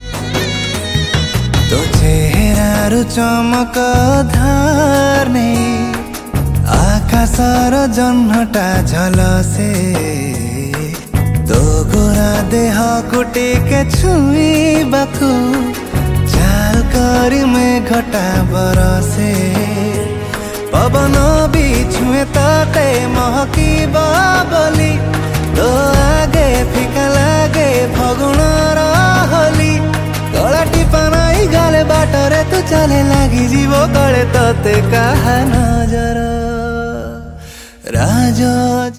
Odia Album Ringtones
dance song